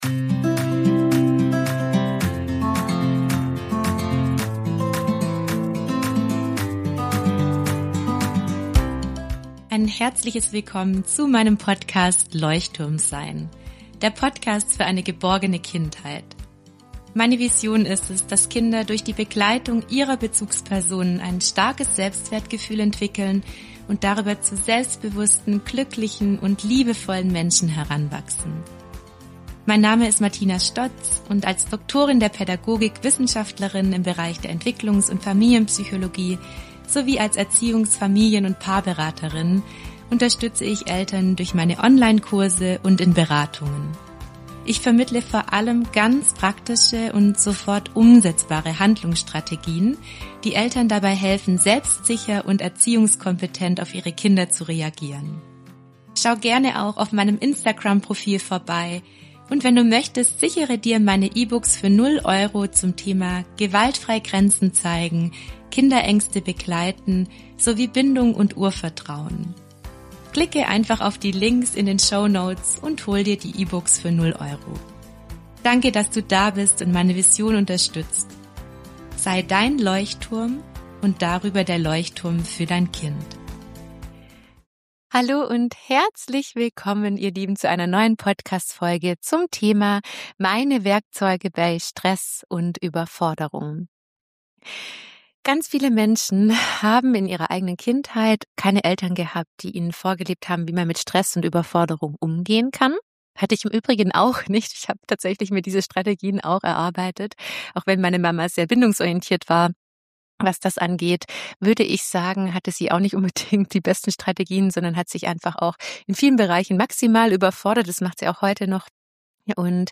in meiner neuen Solo-Folge berichte ich dir von meinen persönlichen Werkzeugen bei Stress und Überforderung.